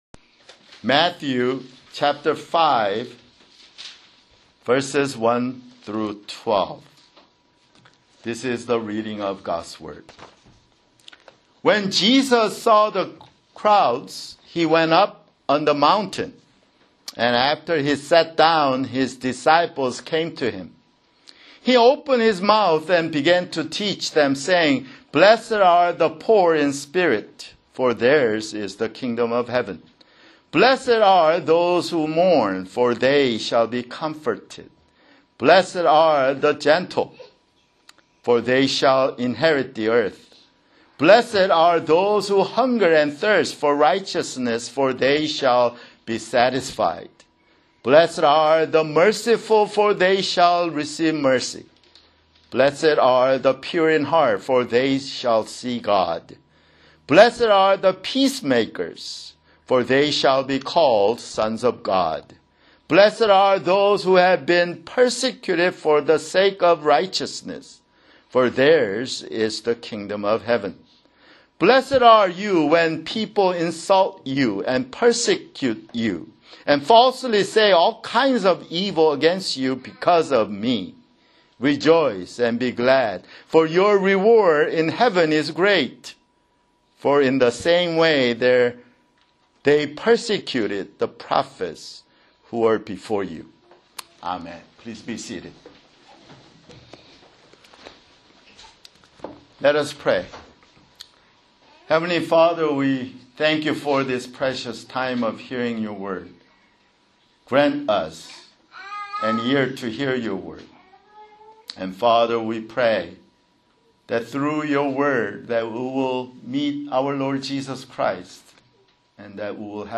[Sermon] Psalms (Intro)